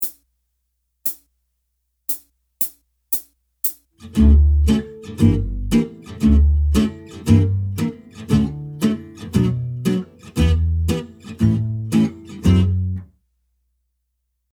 I will give you three examples of ballad rhythms with long duration:
In the first example, notice the pronounced and slow upstroke before beats 1 and 3.